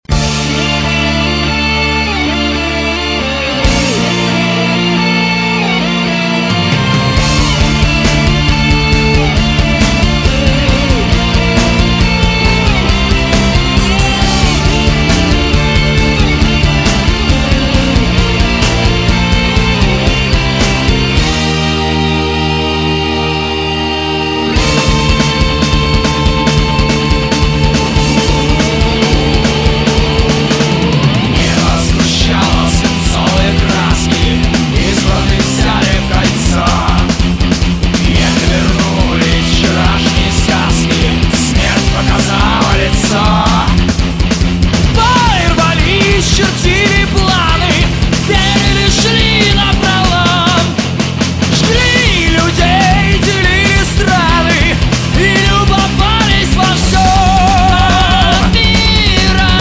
Metal
время драйва и красивых мелодий.